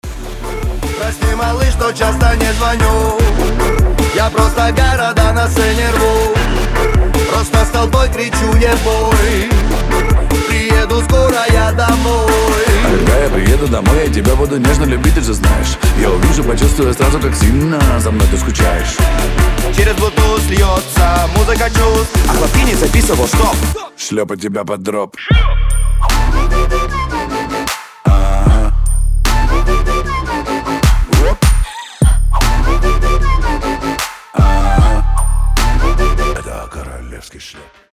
веселые
Trap
басы
качающие
Moombahton